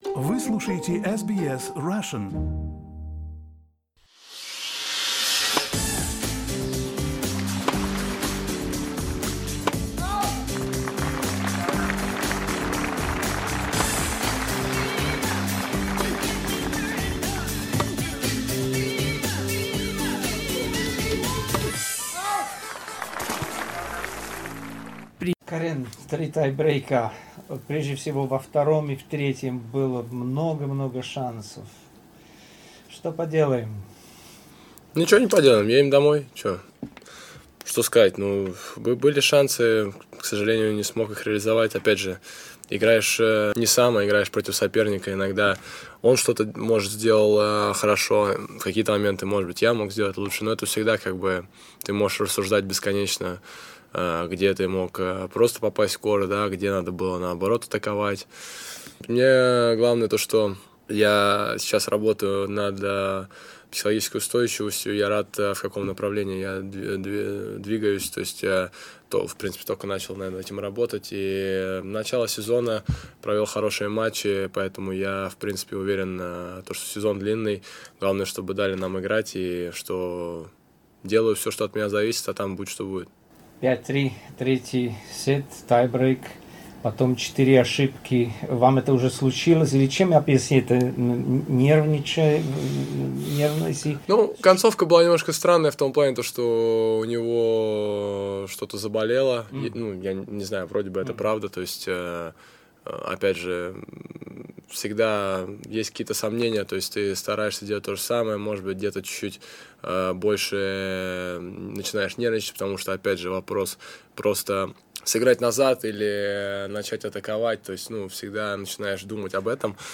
Interview for SBS Russian, in Russian only.